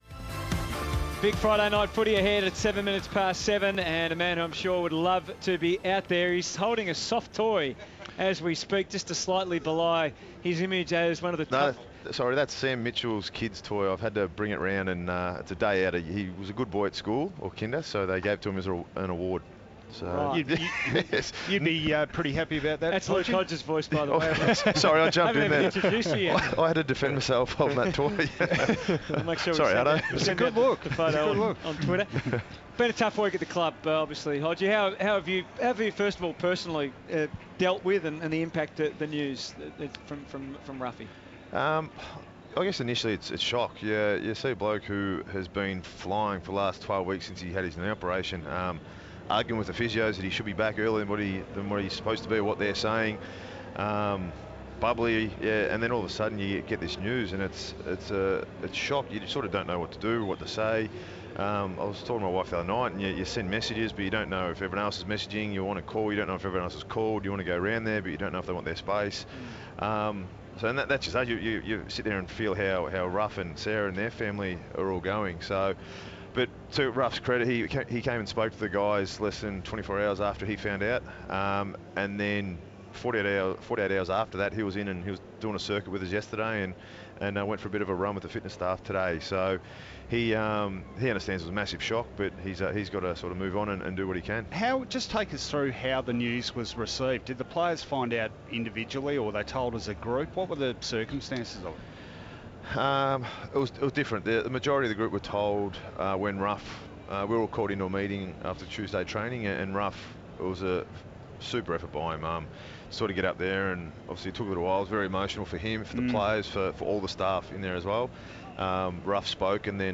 Injured Hawthorn skipper Luke Hodge chats with the boys in the box ahead of Hawthorn v Sydney.